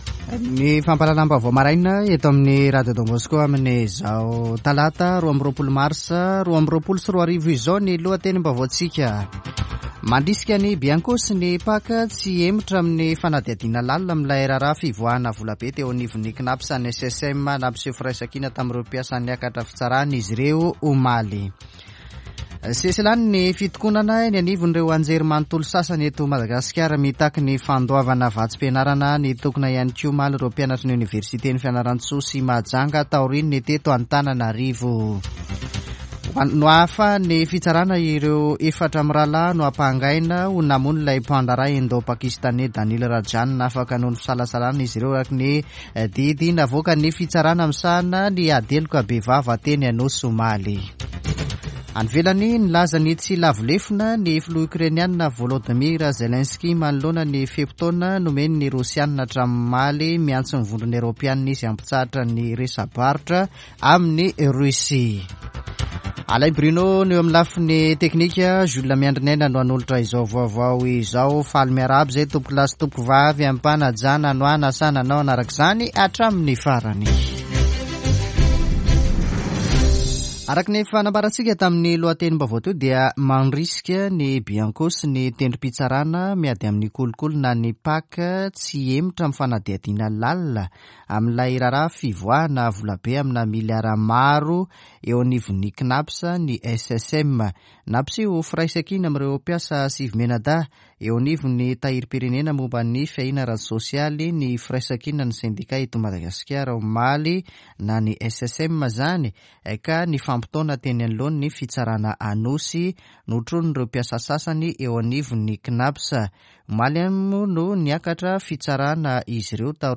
[Vaovao maraina] Talata 22 marsa 2022